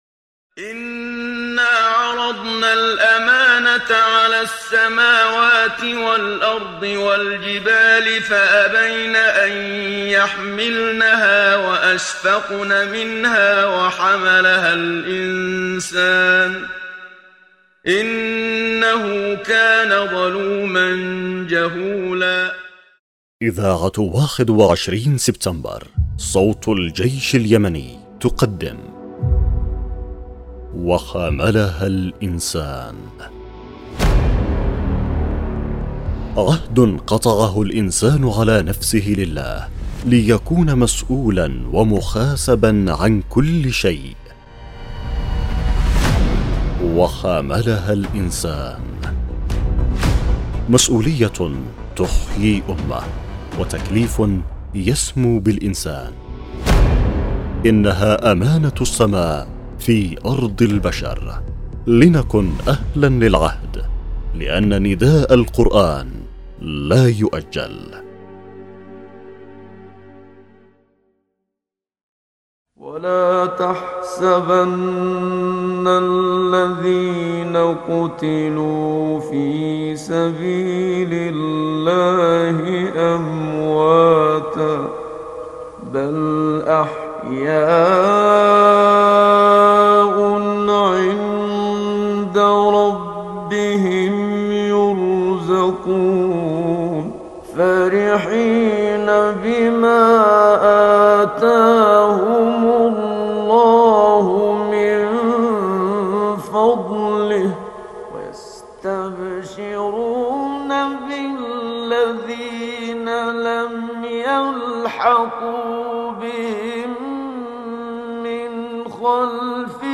برنامج إذاعي يعمل على مناقشة قضايا المسؤولية تجاه كل شيء المسؤولية تجاه الاسرة والأولاد وتجاه الدين والإسلام وتجاه المقدسات وتجاه الشهداء وكل قضية الانسان مسؤول عليها امام الله مع شرح ونقاش عن كل الجوانب التي تشملها تلك المسؤولية